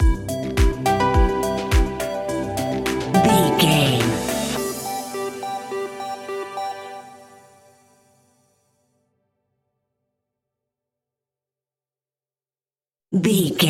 Happy Kids Electro Music Stinger.
Ionian/Major
groovy
uplifting
energetic
synthesiser
drums
electric piano
strings
electronic
instrumentals